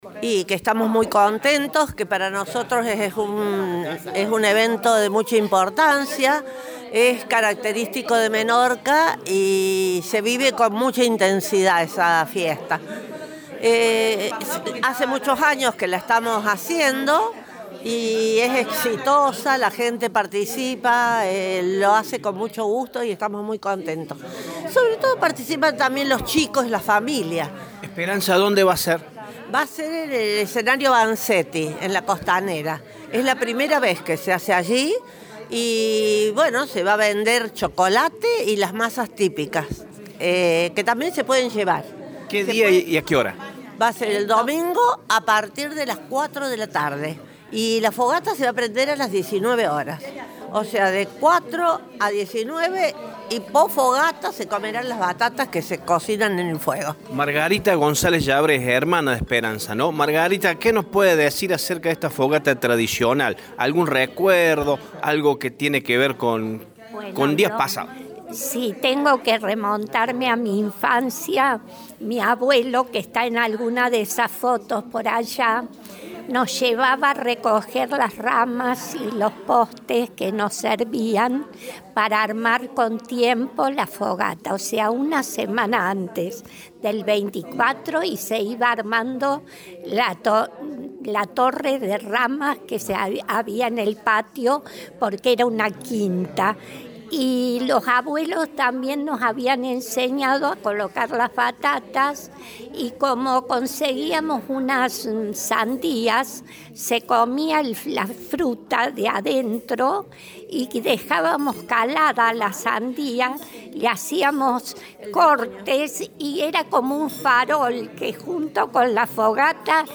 hablaron con Radio Show y contaron detalles de la actividad.